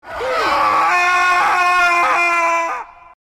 Brainrot Scream Sound Button - Free Download & Play